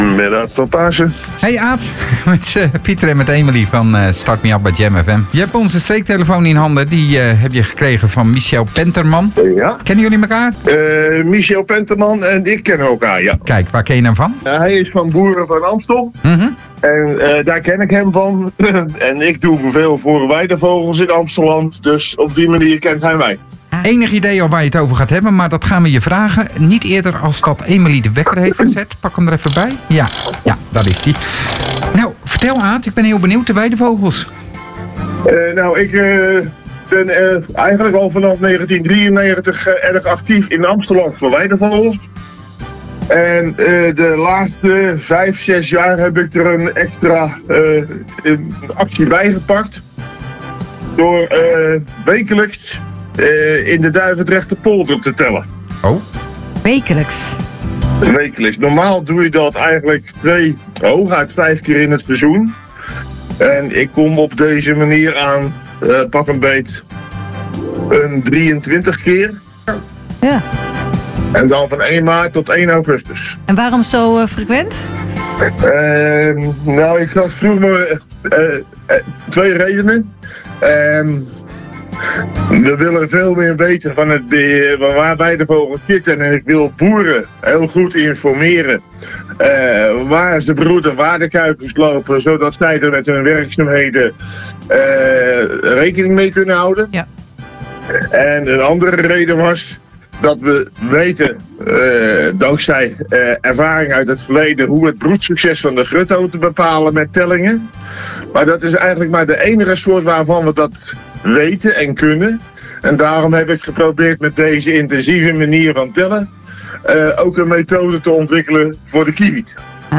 Streektelefoon over de samenwerking tussen boeren en natuurbeschermers, het succesverhaal van de Duivendrechtse polder.